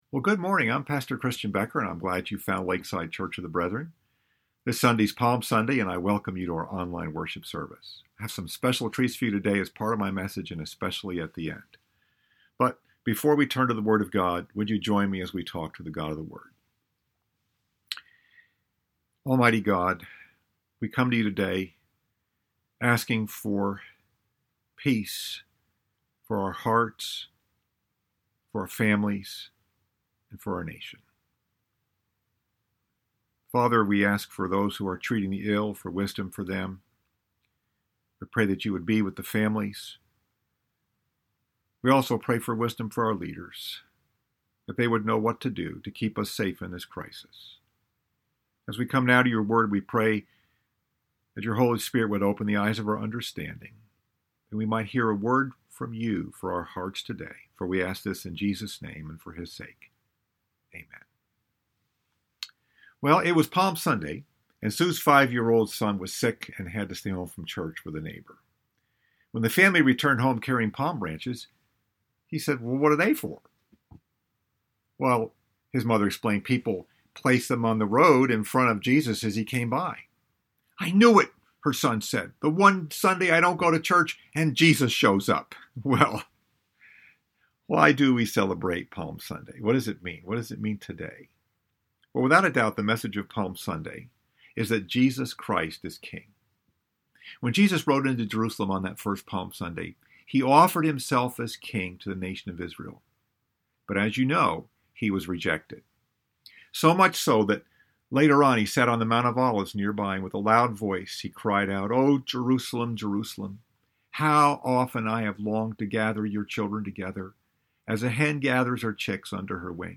Message: “Behold Your King” Scripture: Zechariah 9:9